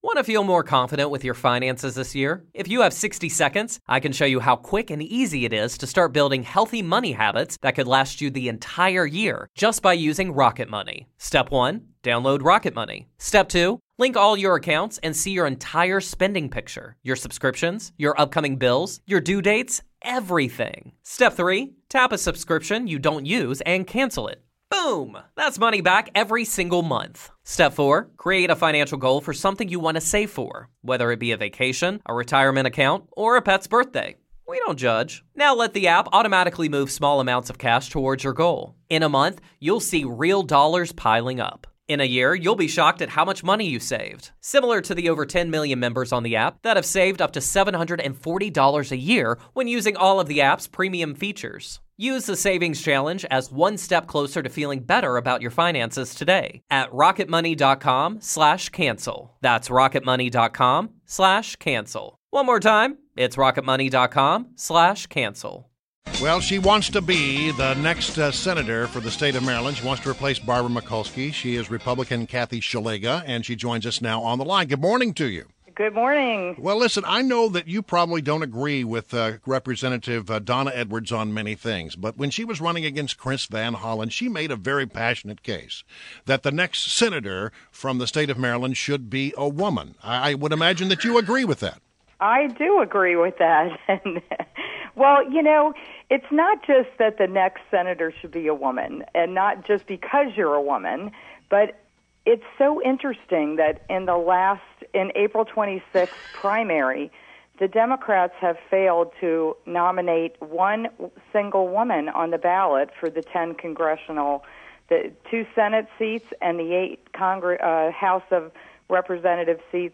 WMAL Interview - KATHY SZELIGA - 06.09.16
INTERVIEW — KATHY SZELIGA — (SH-LEG-GAH) — the minority whip of the House of Delegates and Republican nominee for U.S. Senate• GOP Senate candidate criticizes Trump comments about judge.